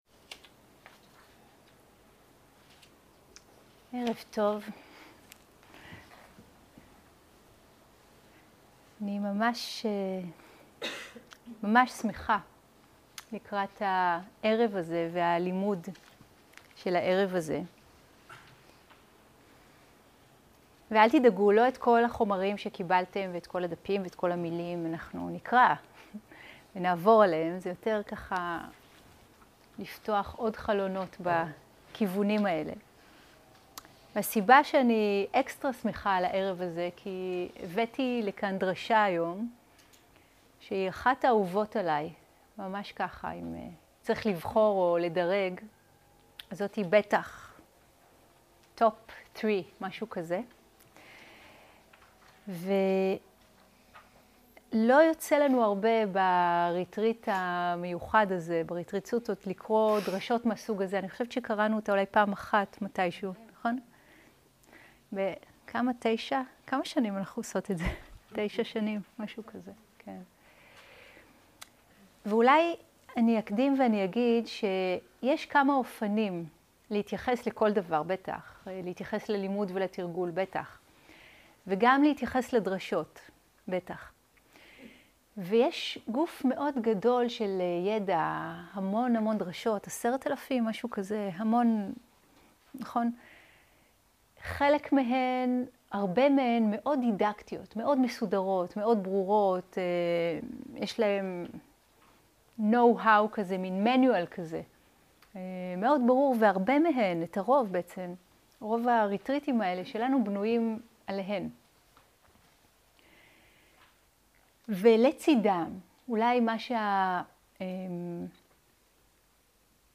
סוג ההקלטה: שיחות דהרמה
עברית איכות ההקלטה: איכות גבוהה מידע נוסף אודות ההקלטה